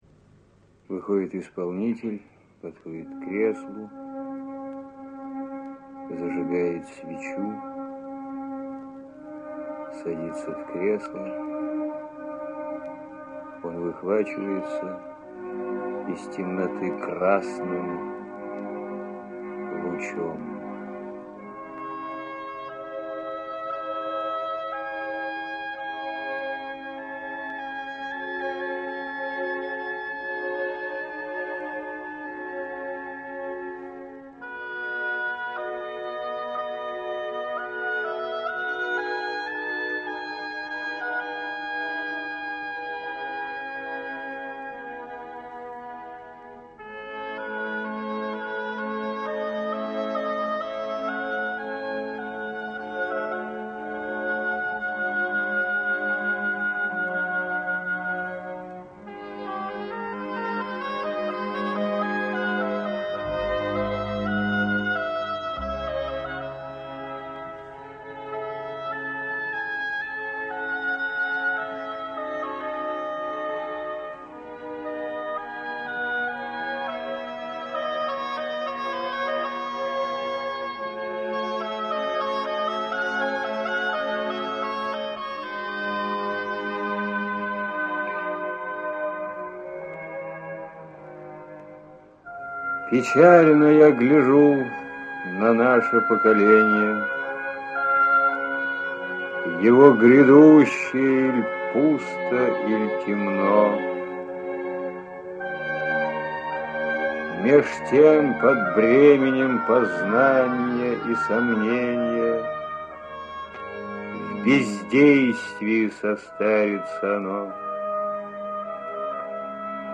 chitaet-oleg-dal-lermontov-m-yu-duma